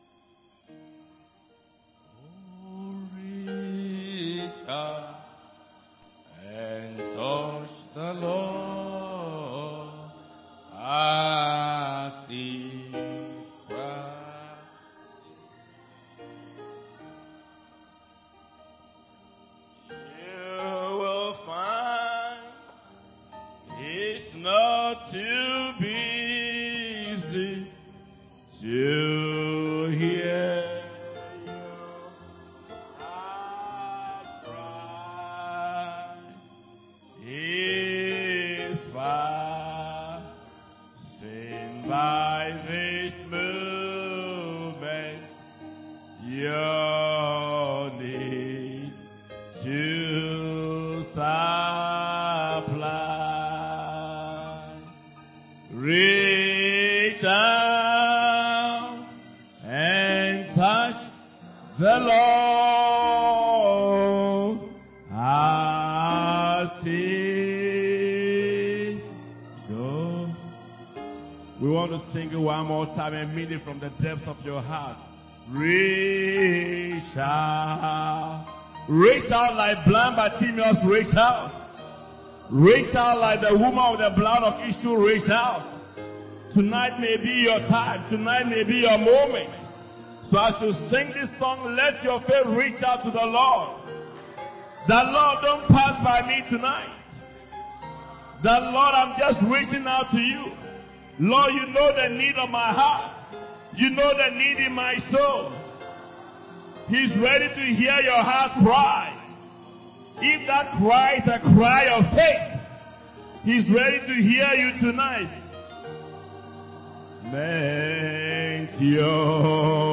Friday Special Meeting 19-09-25